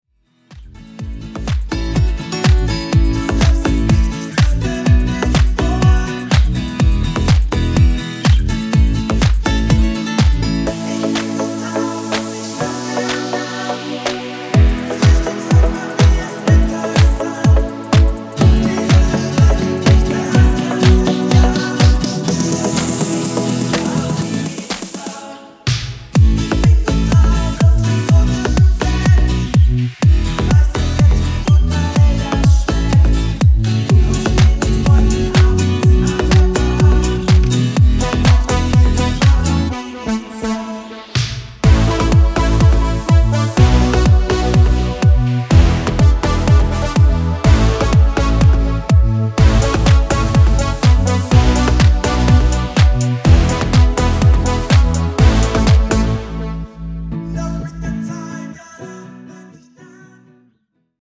Originaltonart: A
Demo in A: